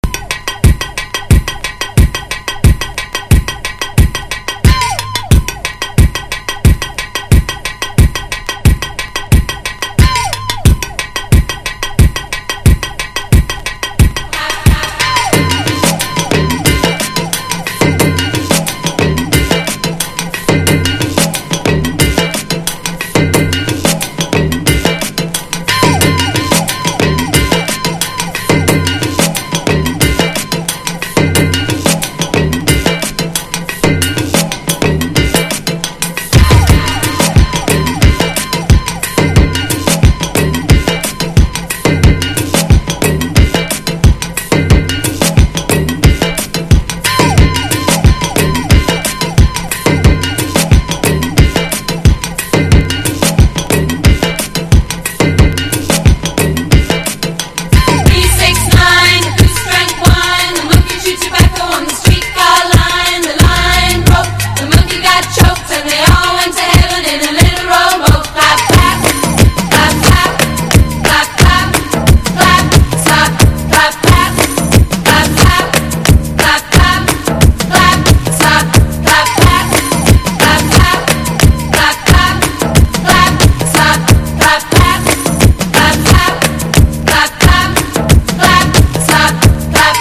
NU-DISCO / RE-EDIT